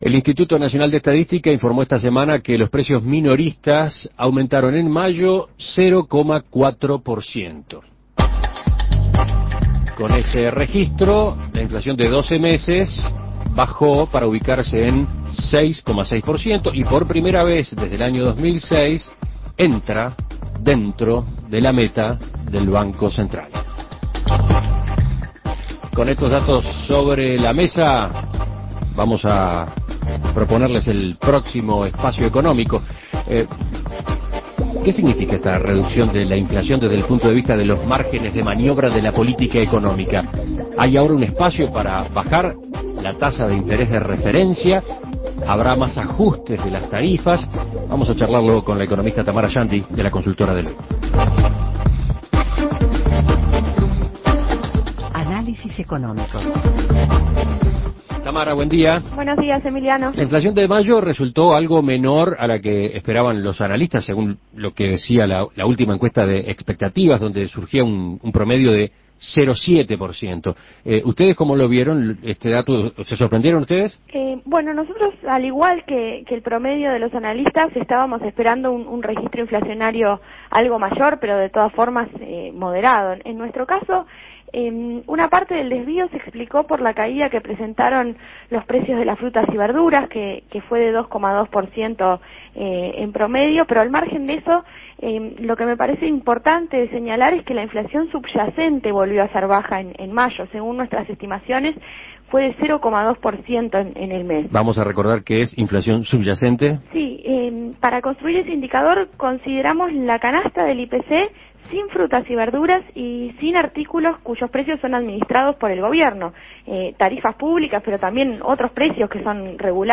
Análisis Económico ¿Hay margen para una rebaja de tasas en el próximo Copom?